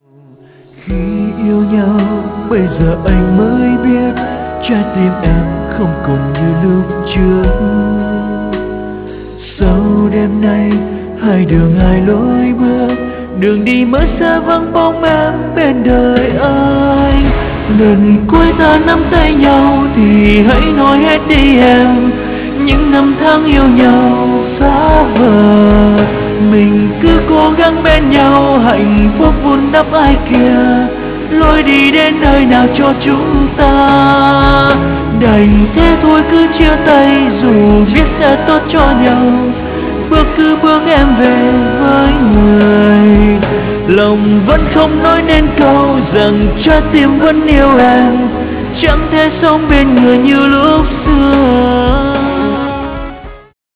chất giọng nam cao, với dòng nhạc pop - ballad